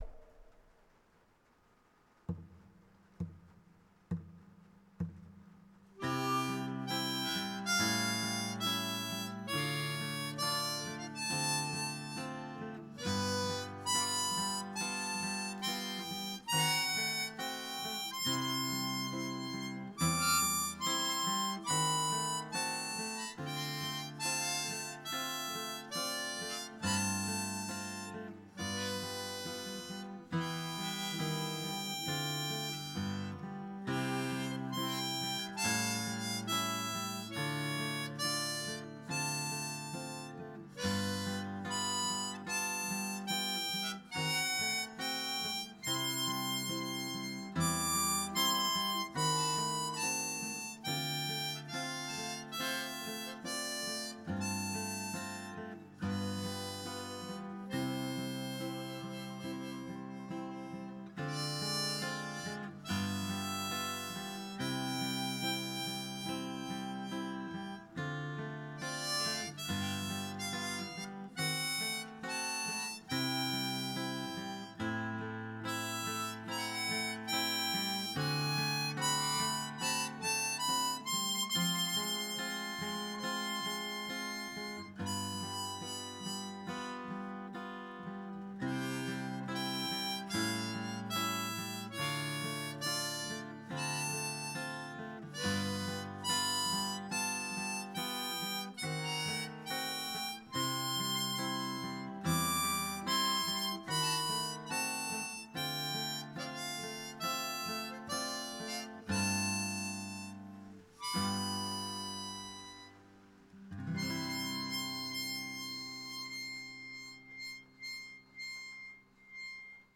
ギターで伴奏を入れてますが、どちらもぶっつけ本番にかなり近い状態で、かなり下手くそです。
クロマチックハーモニカ
ハーモニカは単音を鳴らすのがとても難しく、余計な音がたくさん入ってしまってます。